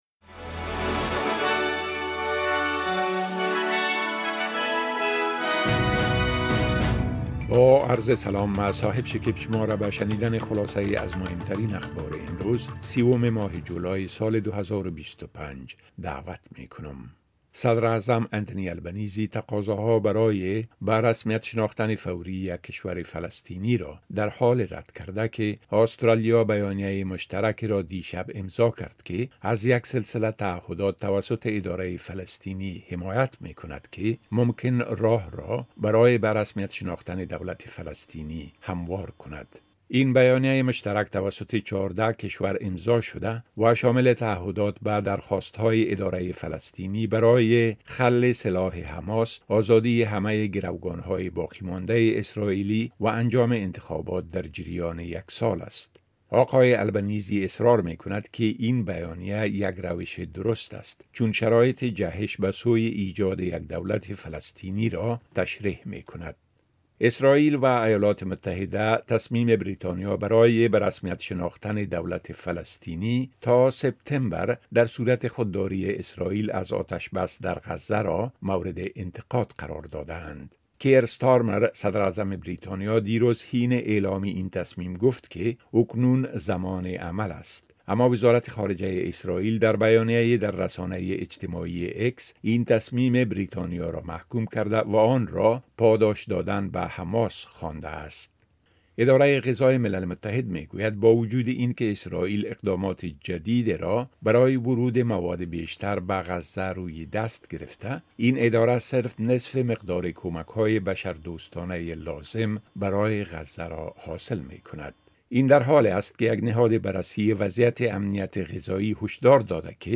خلاصۀ مهمترين خبرهای روز از بخش درى راديوى اس‌بى‌اس